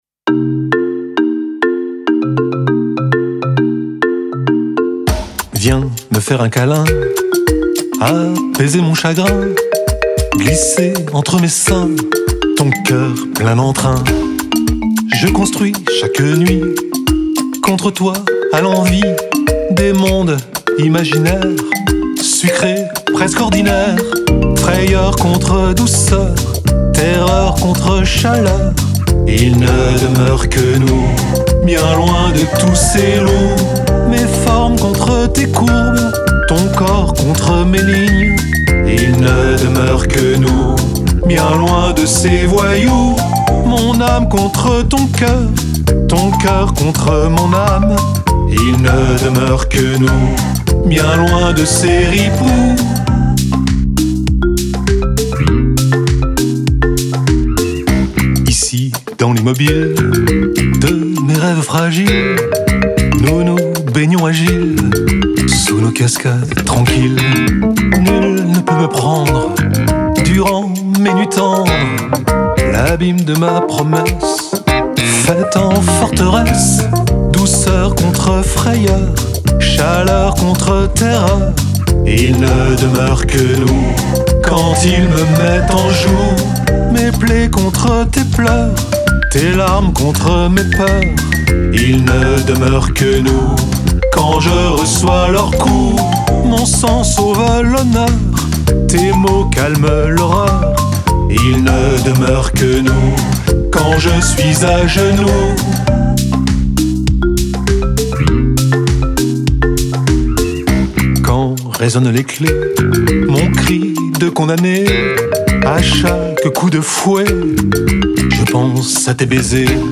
Chant
Guitares
Basse